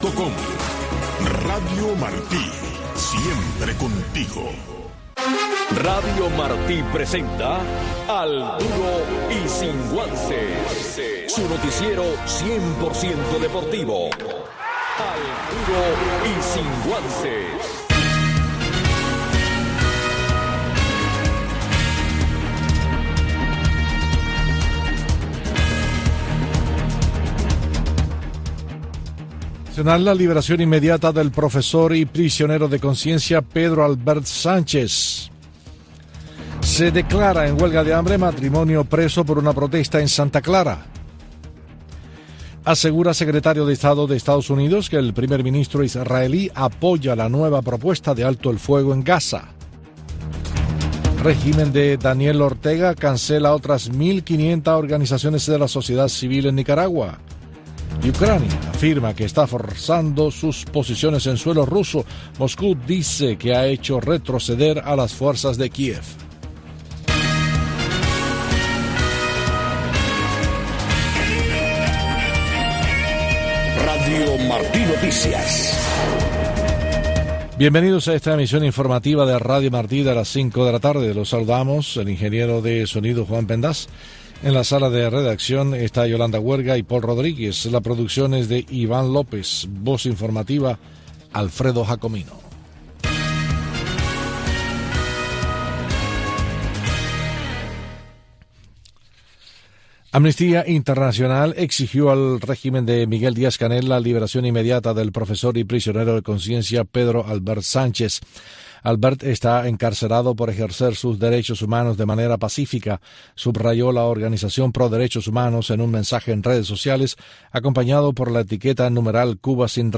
Noticiero de Radio Martí 5:00 PM